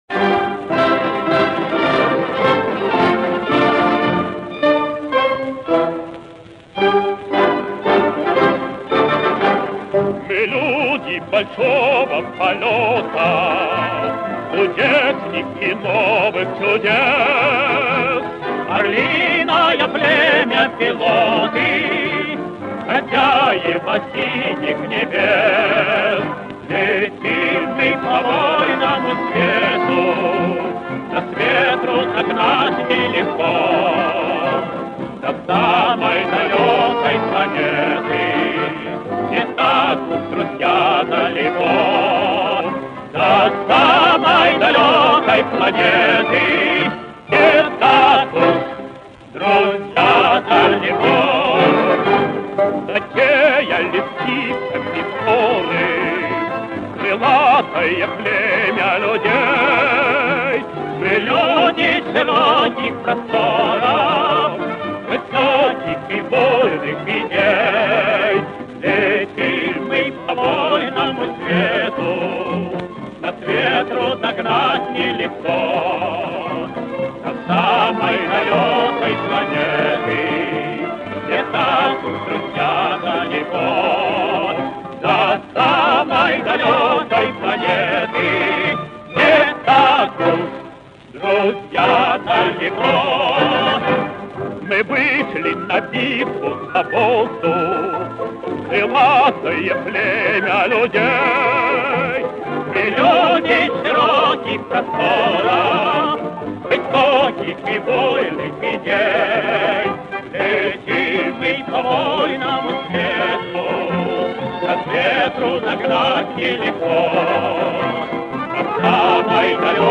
Песня советских летчиков